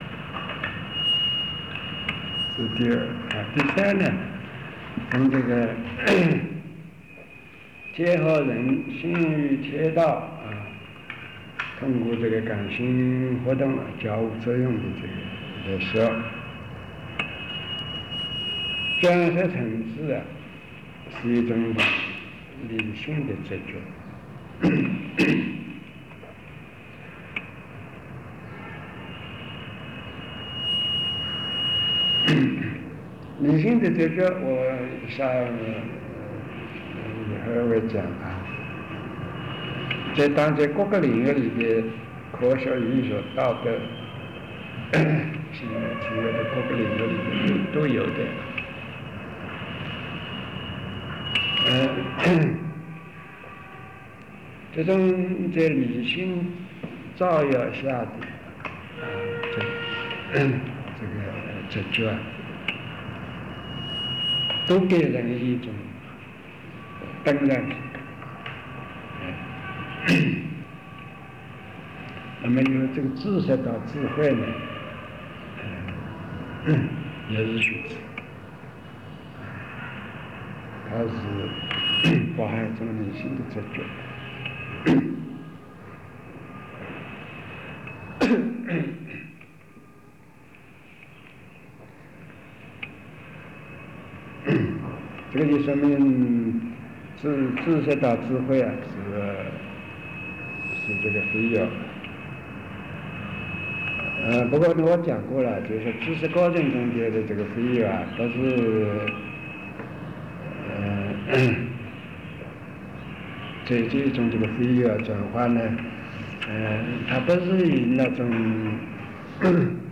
冯契授课录音 第九章第二盘